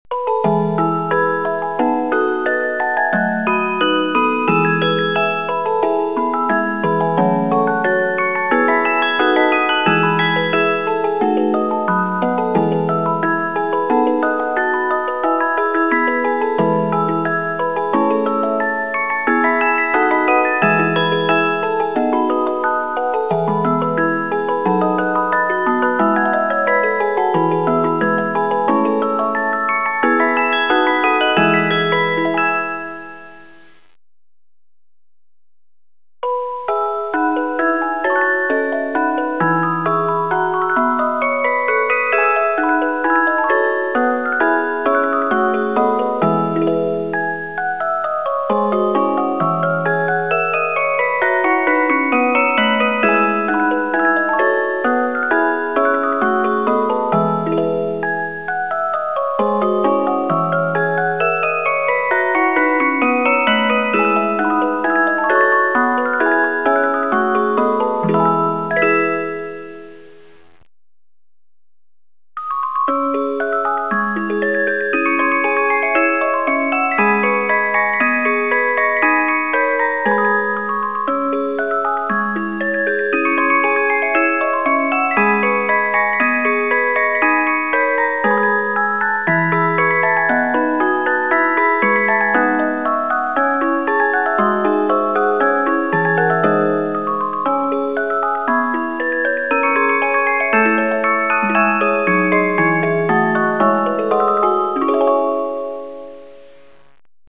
Mouvement : 3x 72 lames
Boîte à musique suisse